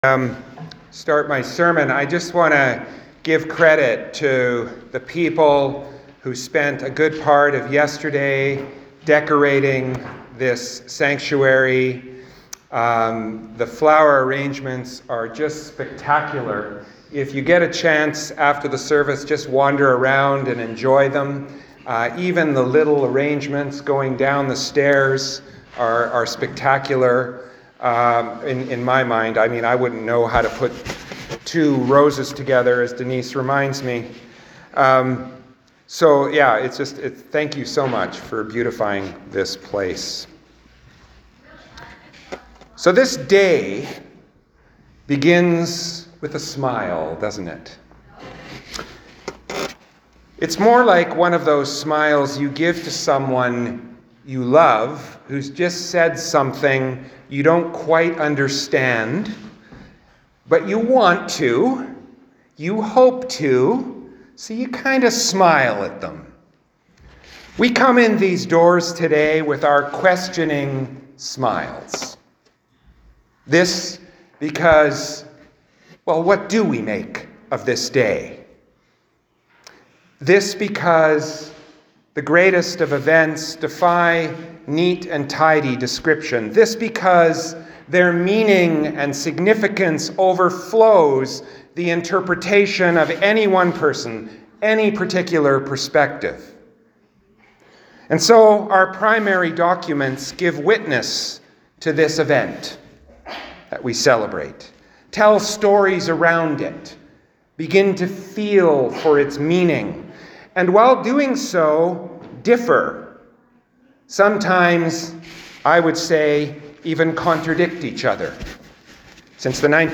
Sermons | St Philip Anglican Church